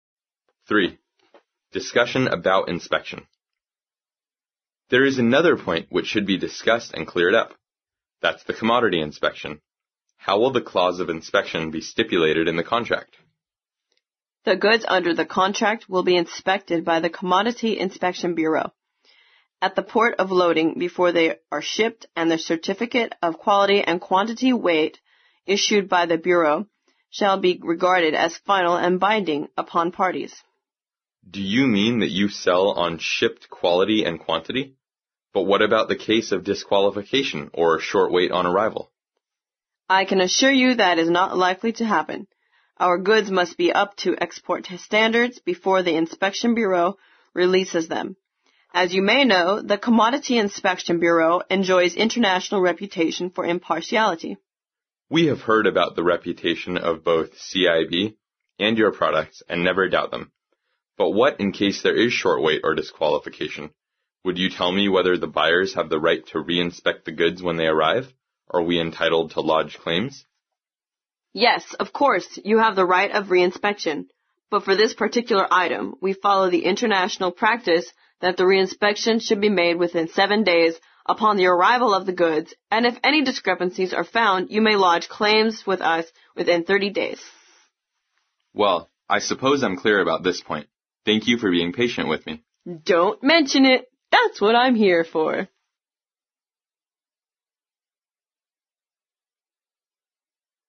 在线英语听力室外贸英语话题王 第121期:商检协议的听力文件下载,《外贸英语话题王》通过经典的英语口语对话内容，学习外贸英语知识，积累外贸英语词汇，潜移默化中培养英语语感。